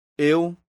Výslovnost a pravopis